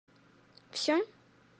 Звуки со словом Всё
Девушка задает вопрос Всё?